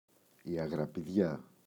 αγραπιδιά, η [aγrapiꞋðʝa]